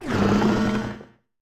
Cri de Terraiste dans Pokémon Écarlate et Violet.